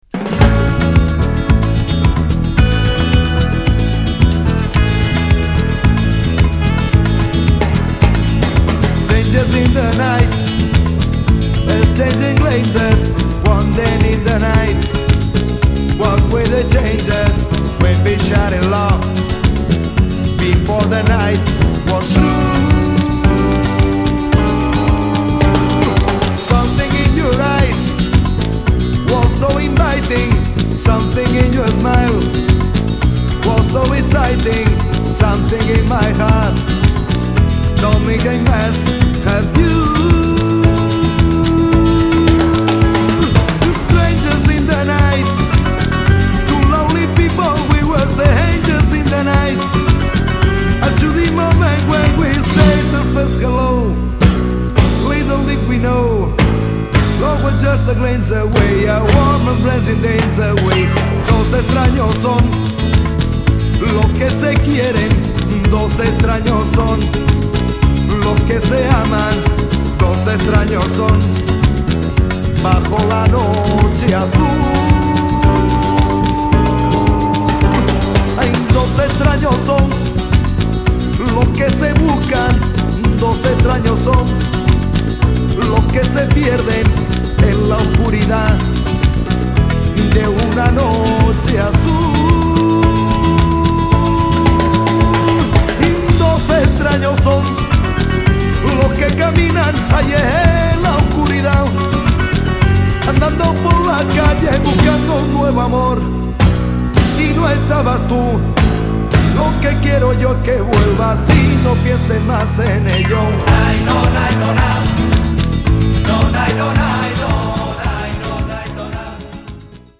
スペインの大所帯ルンバ・グループ